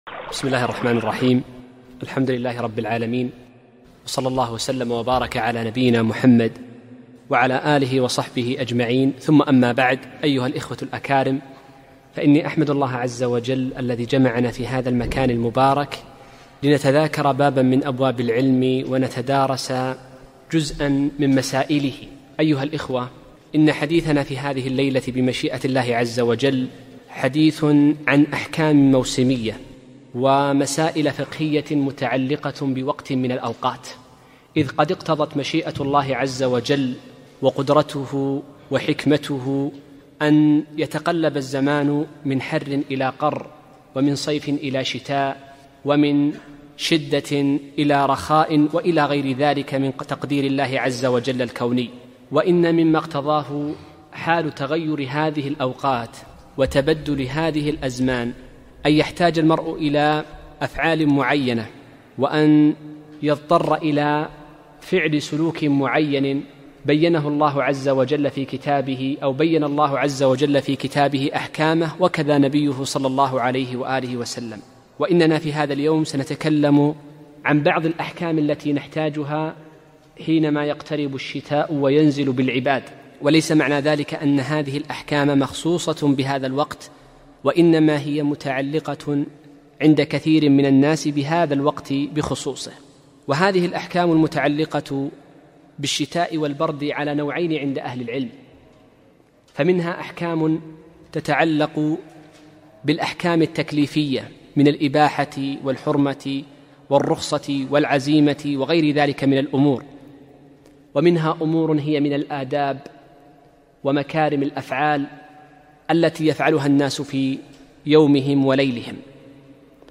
محاضرة نافعة - مسائل مهمة عن الشتاء ومن أحكام المسح على الخفين